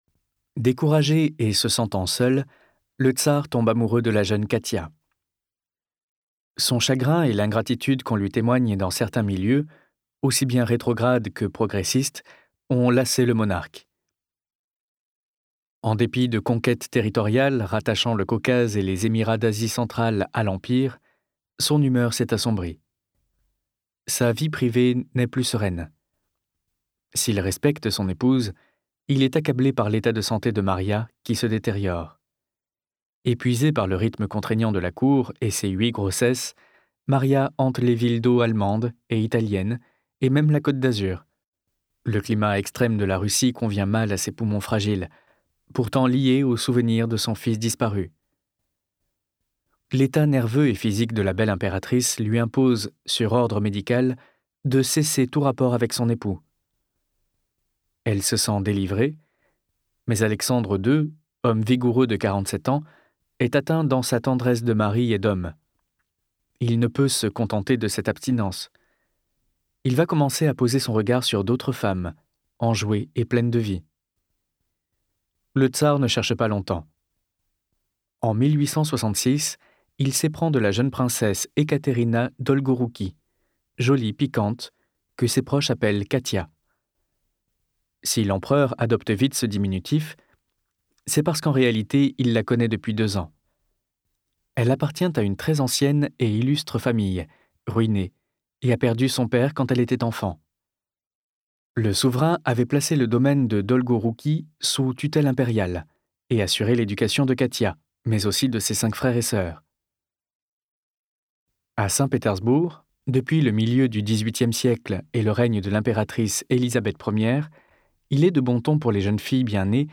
Sa diction est claire, cependant elle ne marque pas de rythme. J’avais l’impression assez désagréable que l’on me lisait un catalogue. La voix du narrateur m’a paru sentencieuse comme s’il me dictait un cours. Sa voix est pourtant agréable. Elle manquait d’intonation, de punch, d’émotion tout simplement.